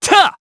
Siegfried-Vox_Attack3_jp.wav